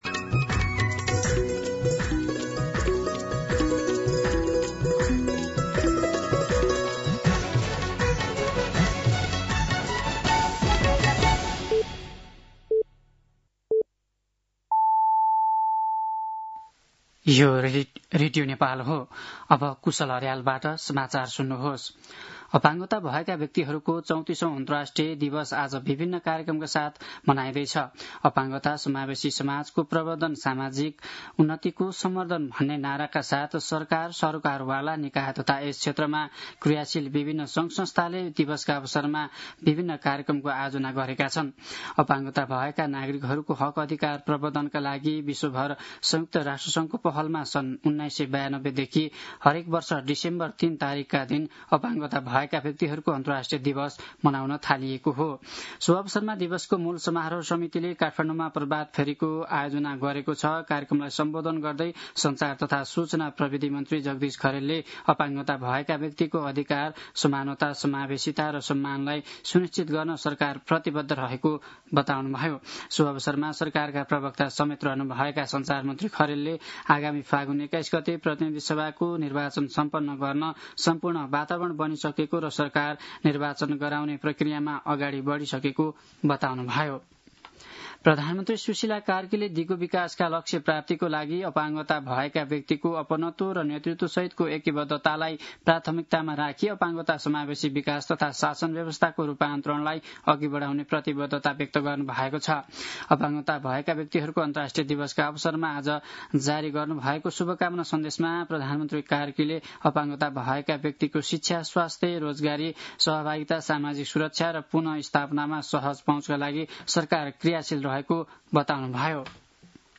दिउँसो ४ बजेको नेपाली समाचार : १७ मंसिर , २०८२
4-pm-News-8-17.mp3